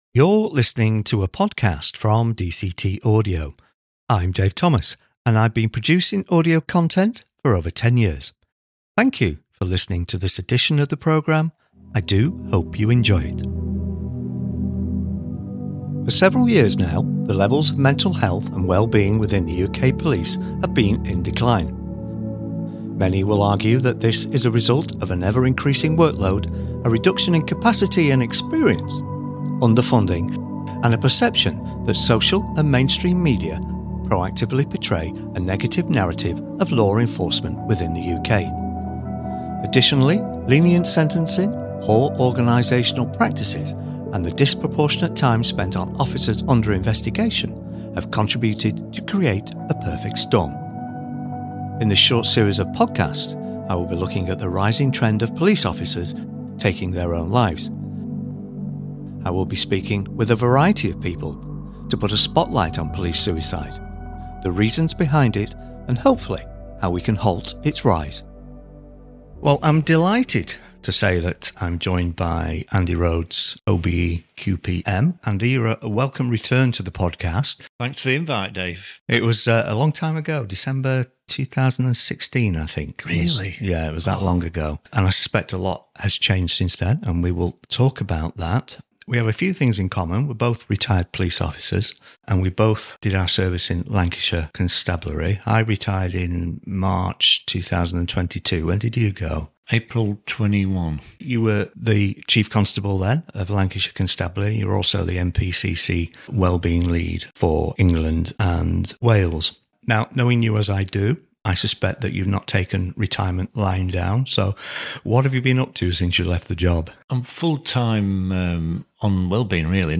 In this episode I talk with the former Chief Constable of Lancashire Constabulary and the current programme director of Oscar’ Kilo, Andy Rhodes OBE QPM.